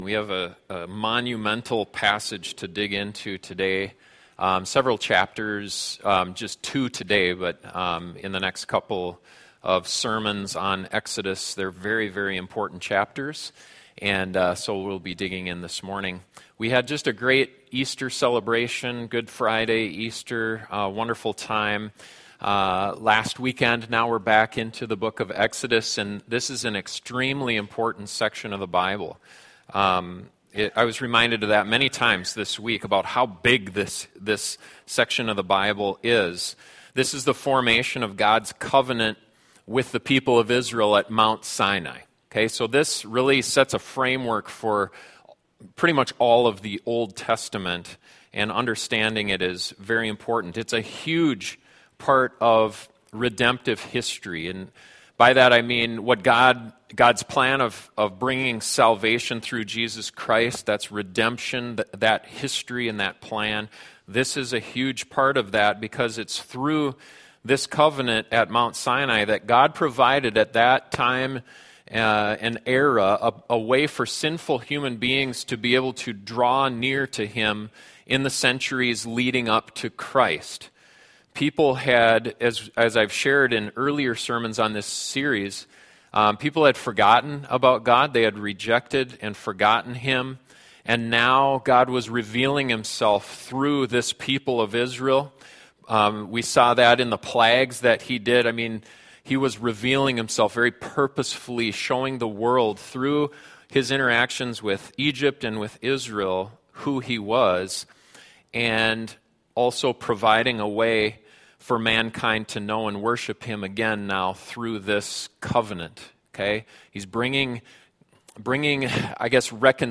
The Bible says it’s going to happen and that it will be a vastly different experience for people depending on their relationship with Jesus Christ. This sermon will focus on the glory of God and why it’s so critically important to have Jesus as our Mediator.